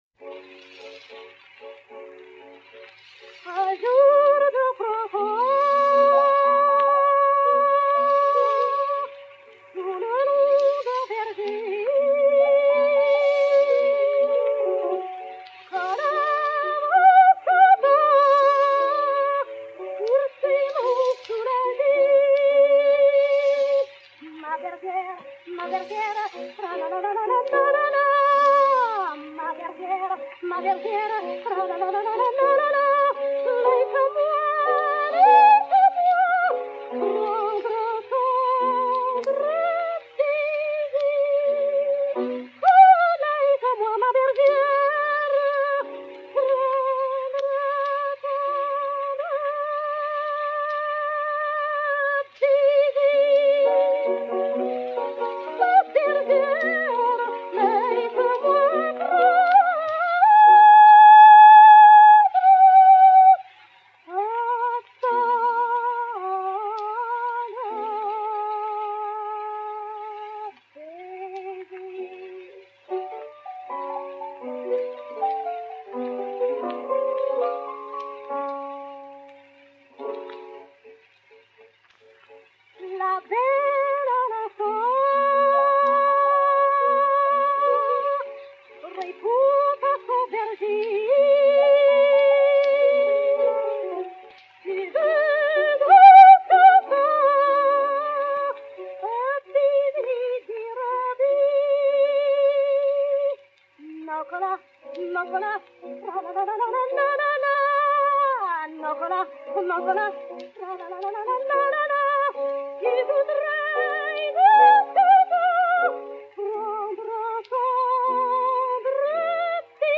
Mélodie, poésie de Jean-François REGNARD, musique de Georges BIZET (juillet 1868).
Dame Nellie Melba, soprano, accompagnée par sir Landon Ronald au piano
Disque pour Gramophone 03070, mat. 691c, enr. à City Road, Londres, le 07 juillet 1906